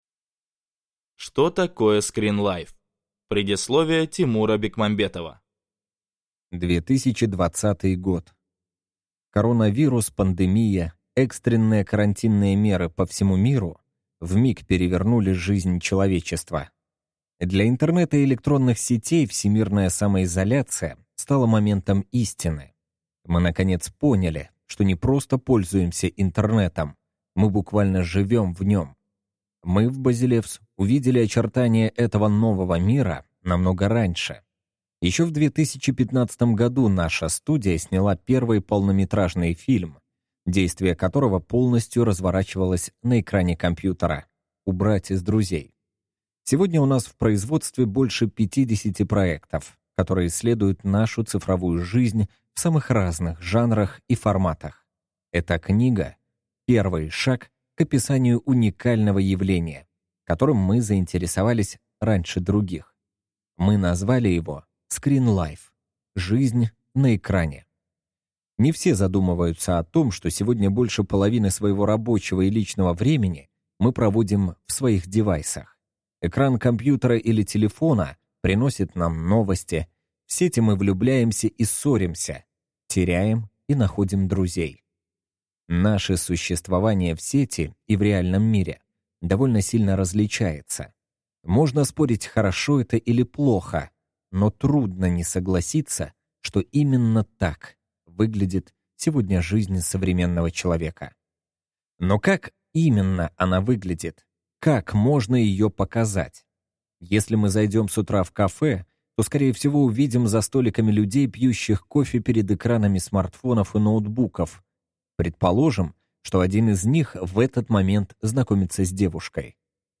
Аудиокнига Скринлайф. В поисках нового языка кино | Библиотека аудиокниг